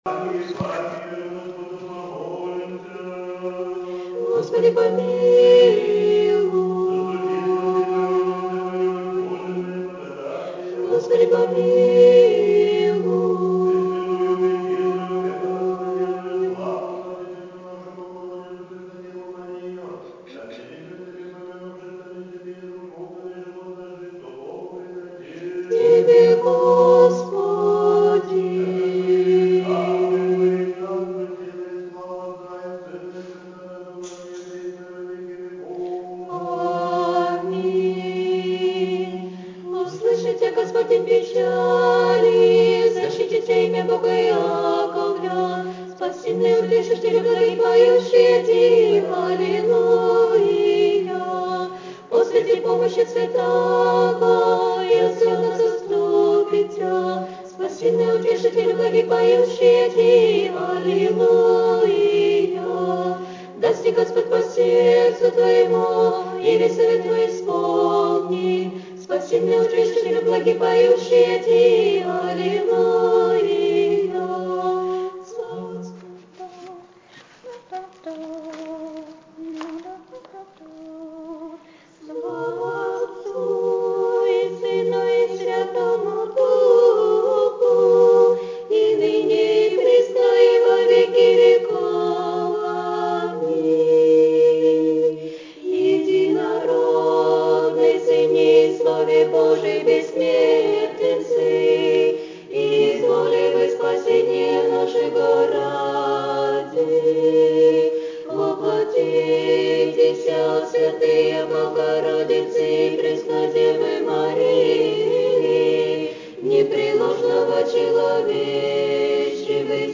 День Святой Троицы, Пятидесятницу встретил дружный Приход храма «Всех скорбящих Радость» | «Всех Скорбящих Радость» в Сосновке
На всенощнем бдении весь храм с воодушевлением молился и пел долгожданную молитву «Царю Небесный…», а утром, как всегда, была Божественная Литургия.
liturgia_Troica_2015.mp3